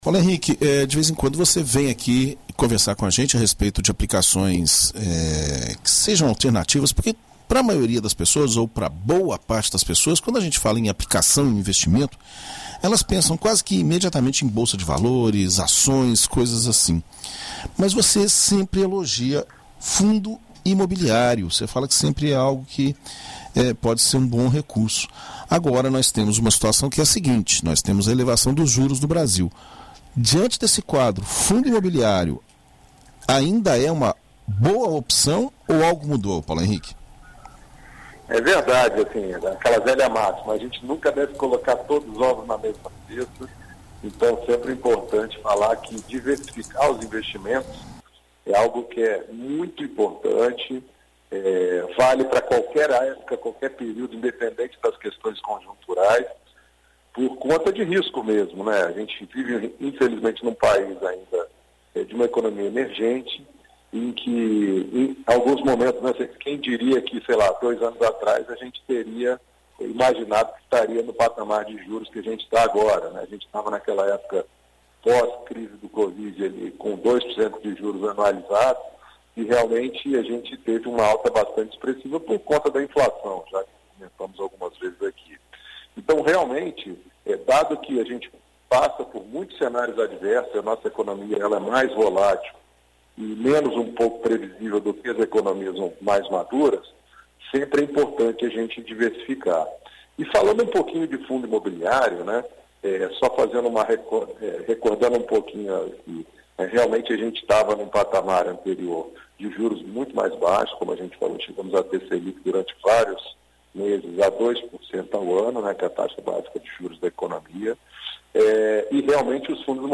Na coluna Seu Dinheiro desta terça-feira (19), na BandNews FM Espírito Santo